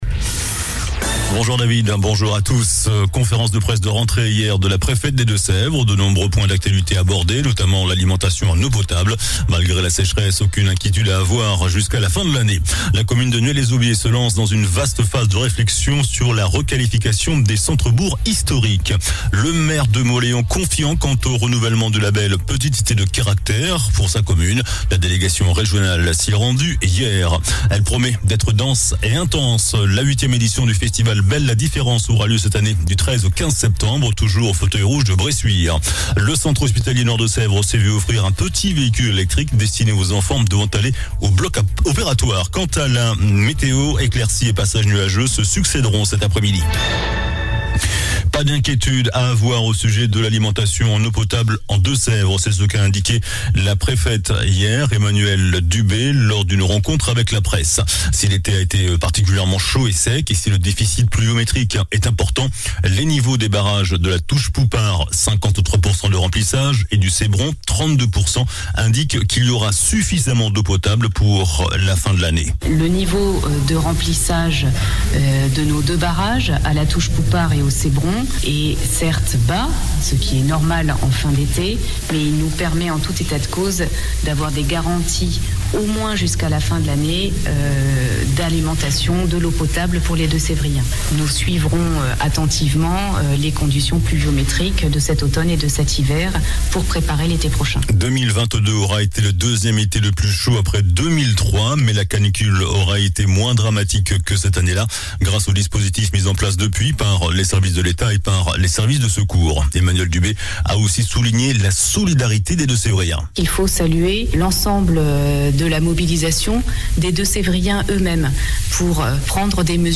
JOURNAL DU JEUDI 08 SEPTEMBRE ( MIDI )